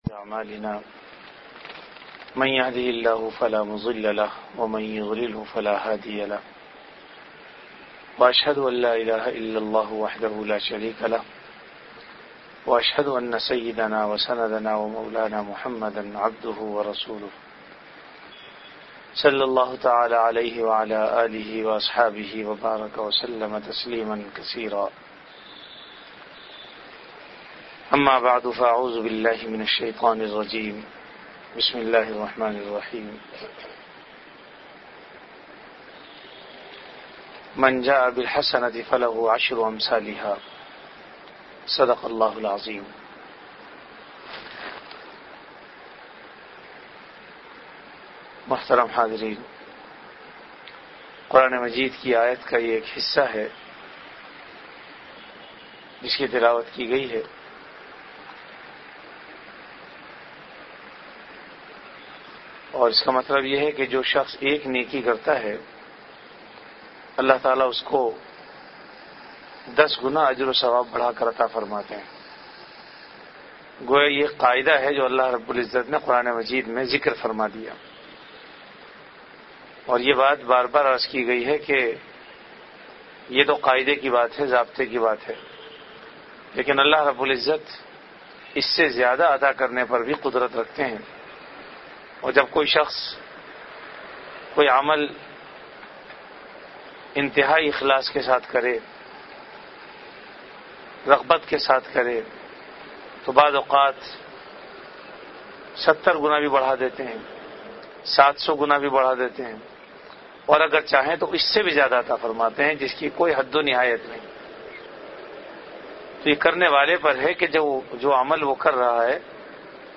Bayanat · Jamia Masjid Bait-ul-Mukkaram, Karachi